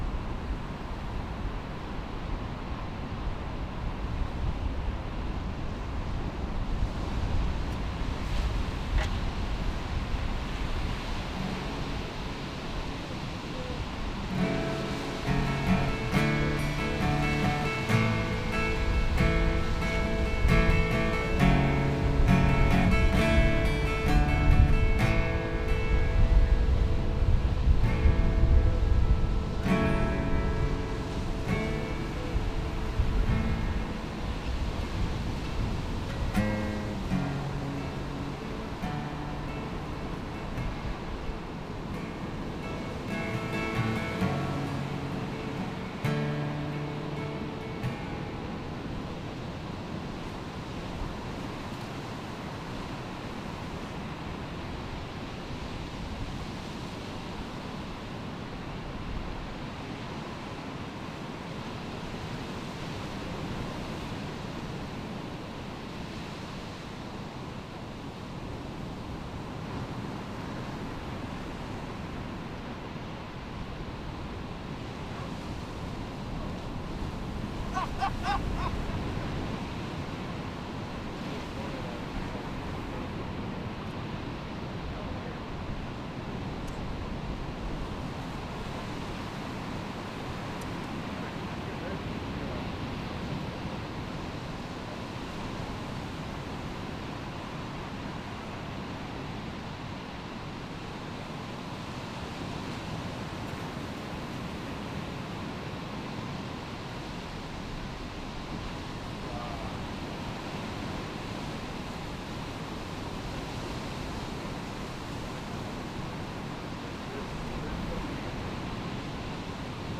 SERMON DESCRIPTION Advent means “coming,” reminding us that even when hope feels deferred, God’s promise in Jesus never fails.